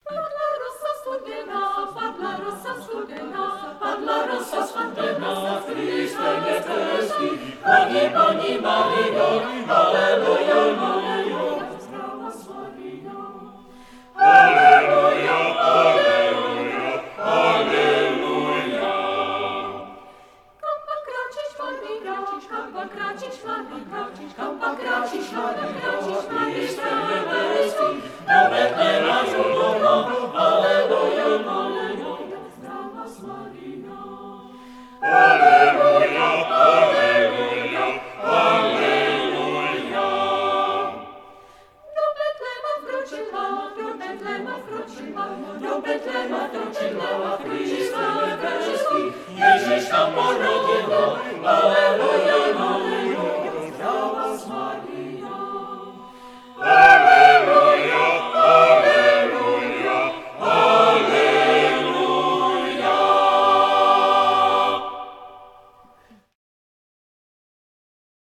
The list origins from concerts performed from 1971.
Moravská koleda